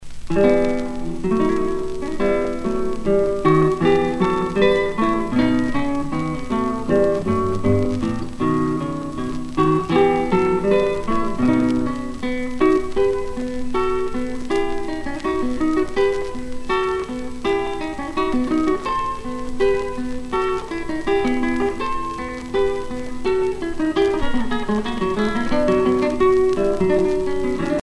400 years of the classical guitar
Pièce musicale éditée